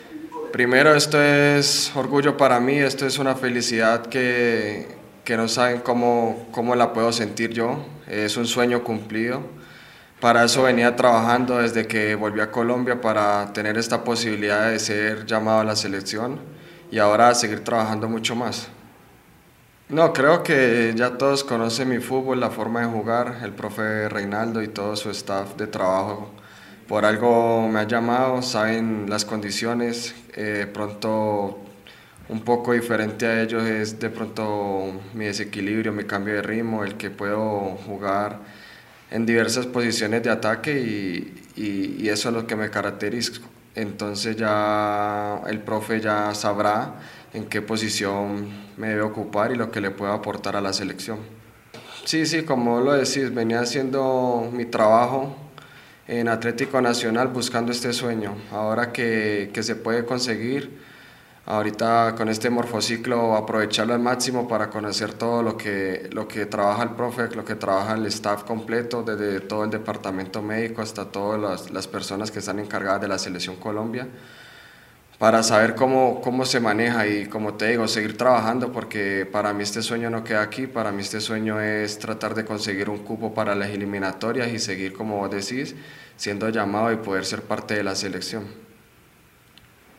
Andrés Andrade en rueda de prensa